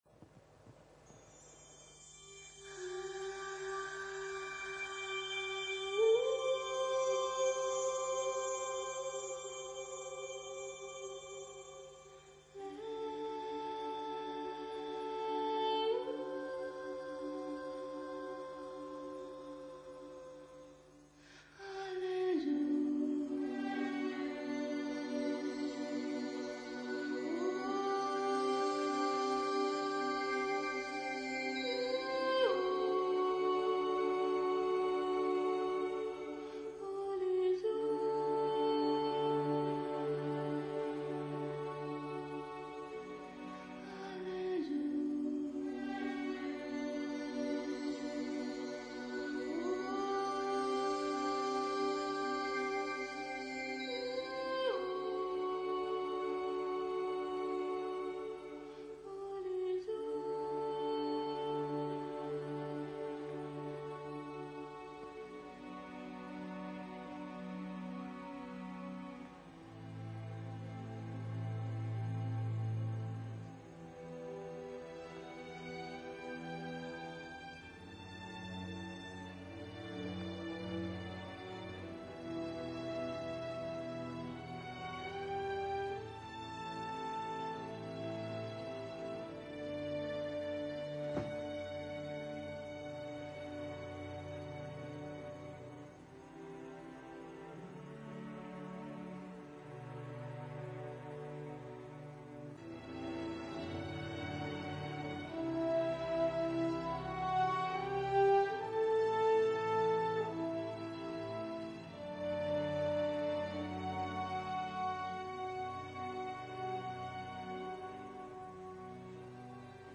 آهنگ تیتراژ با صدای